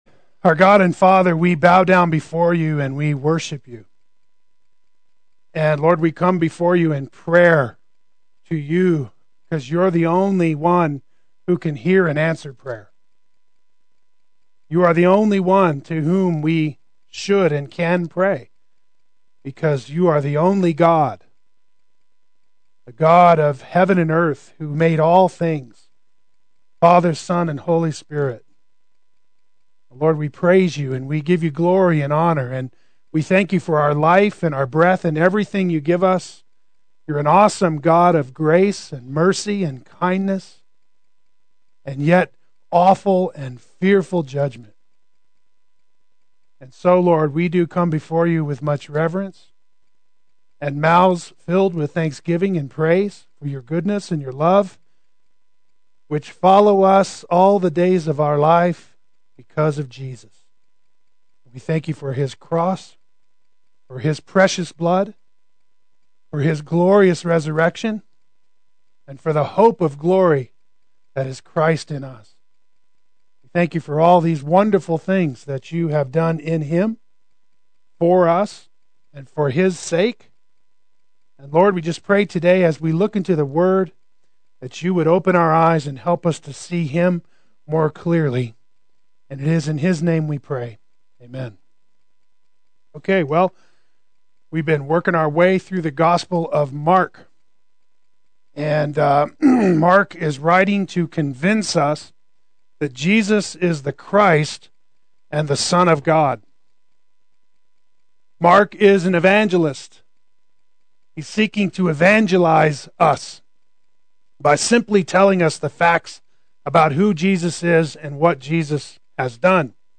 the Son of God” Adult Sunday School